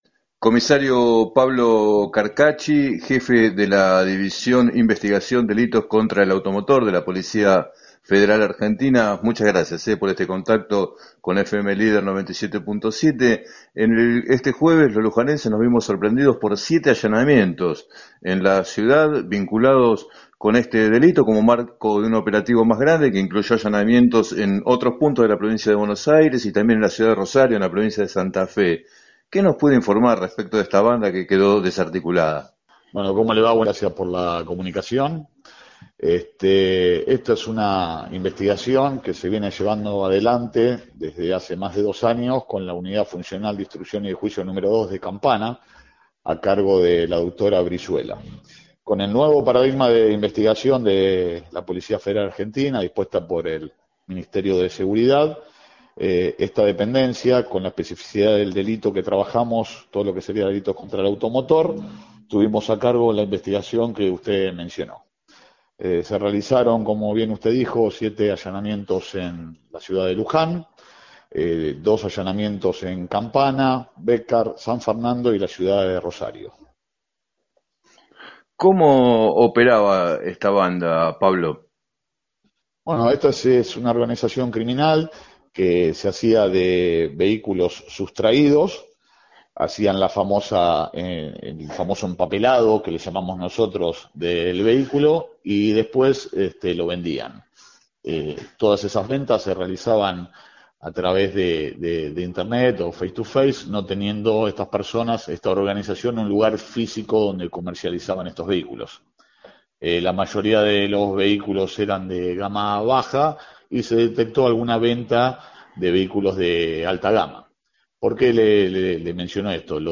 En declaraciones al programa “Planeta Terri” de FM Líder 97.7, el alto jefe policial explicó que la organización criminal contaba en esta ciudad con los servicios de talleres mecánicos y de una gestoría y que también estaban involucrados un hermano y una hija del cabecilla.